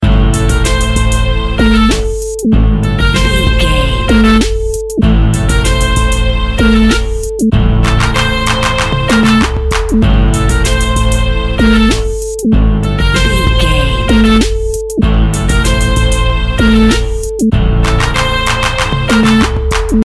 Phrygian
middle east music
hammond organ
synth drums
synth leads
synth bass